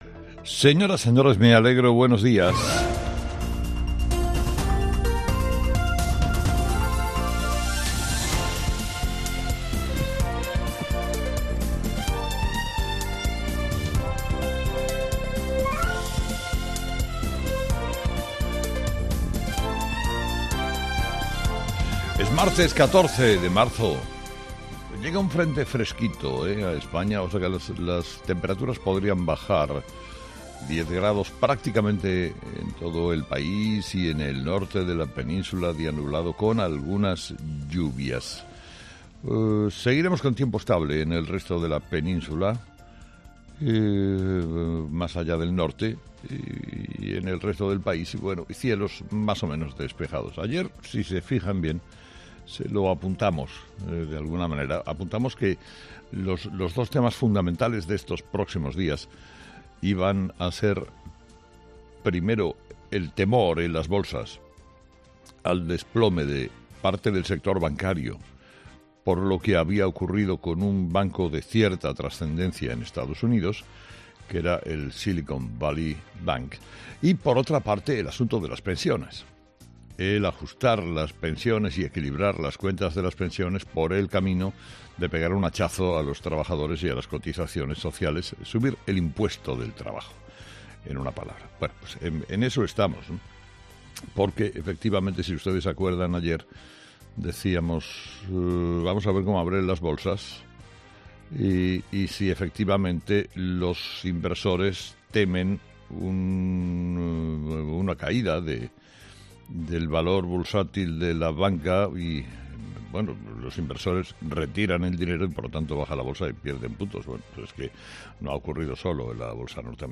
Carlos Herrera, director y presentador de 'Herrera en COPE', comienza el programa de este martes analizando las principales claves de la jornada, que pasan, entre otros asuntos, por "el desplome de parte del sistema bancario por lo sucedido con un banco de Estados Unidos, el Silicon Valley Bank".